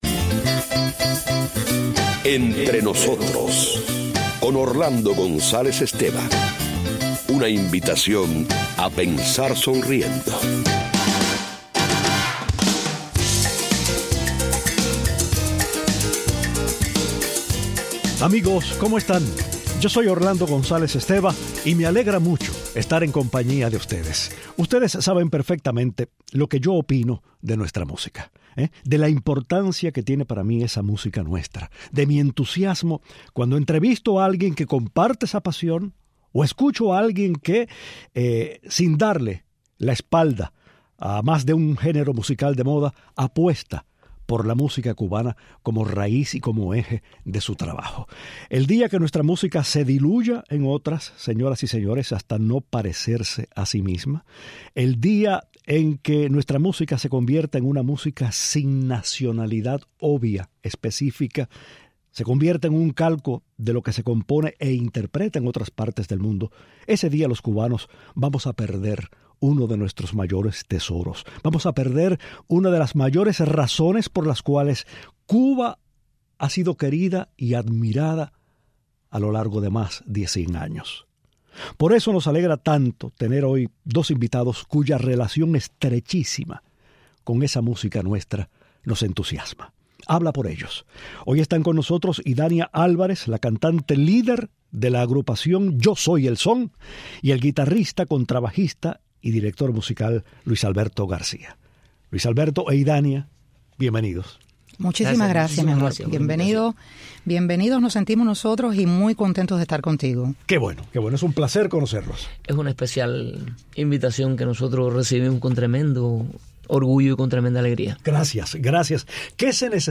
Los excelentes intérpretes de la agrupación "Yo Soy el Son" hablan de este ritmo, sus vidas, su trabajo, cantan un par de canciones en vivo y anuncian la próxima salida al mercado de un disco y un vídeo clip.